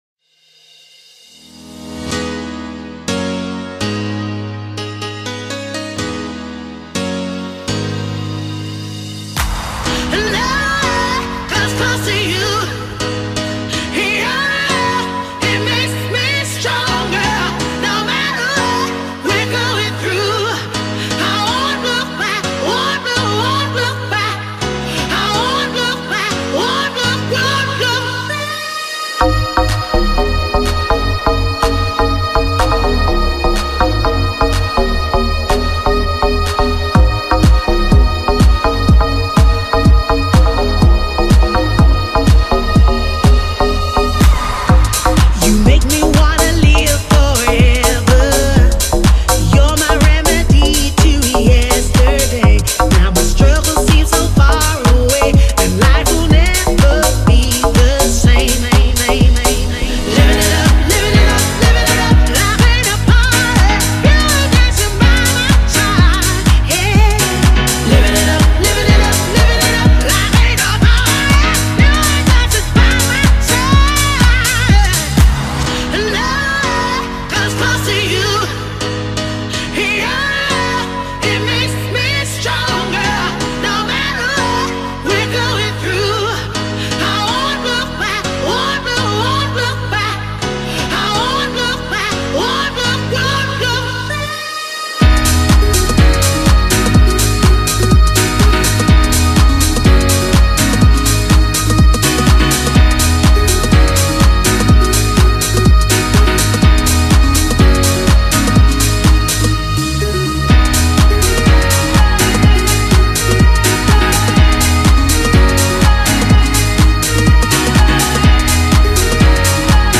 BPM124
Audio QualityCut From Video